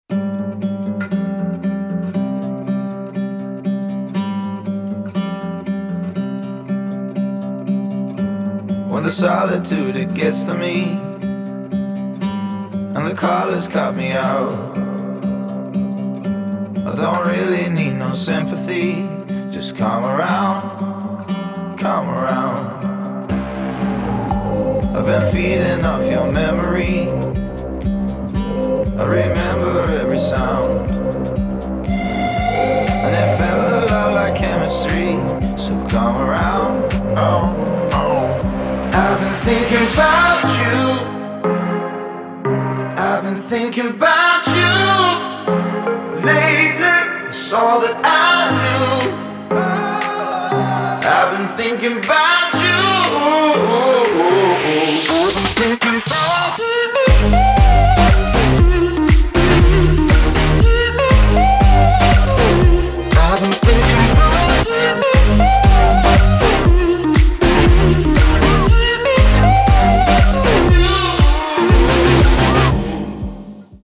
• Качество: 224, Stereo
гитара
мужской вокал
Electronic
спокойные
future house